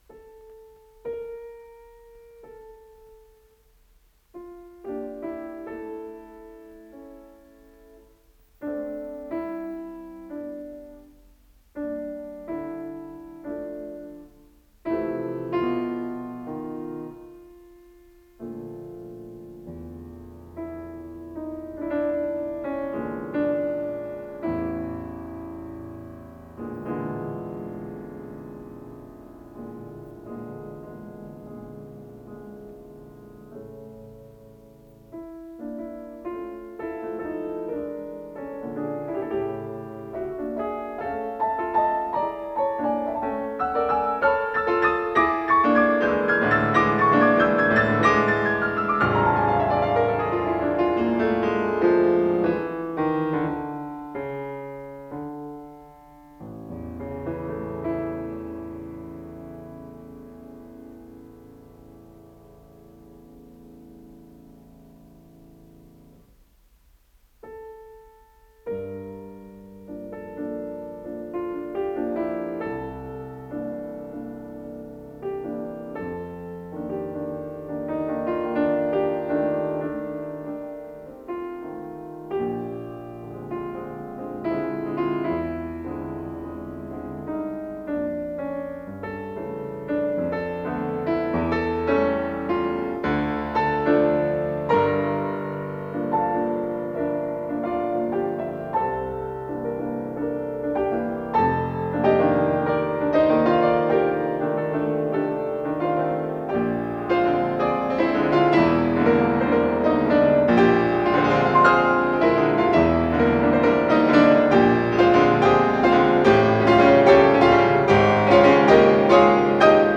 с профессиональной магнитной ленты
ИсполнителиЕвгений Светланов - фортепиано
ВариантДубль моно